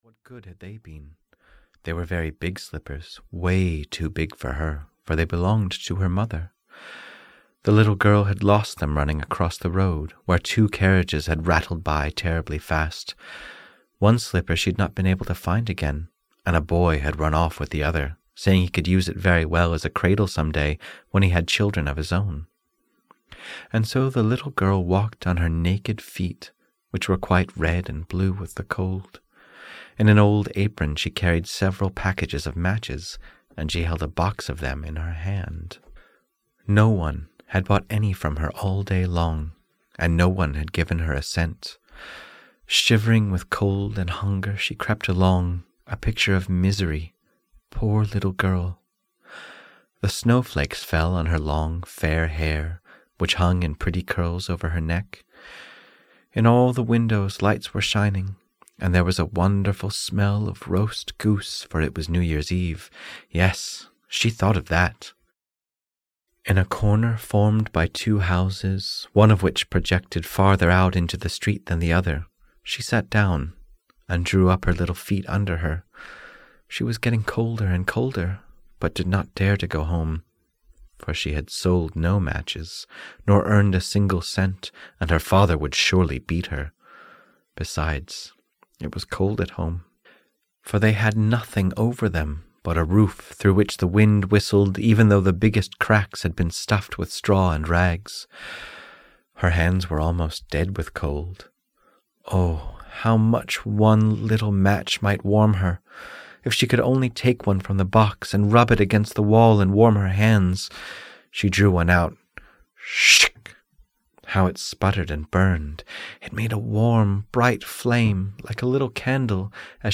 Tales About Hope (EN) audiokniha
Ukázka z knihy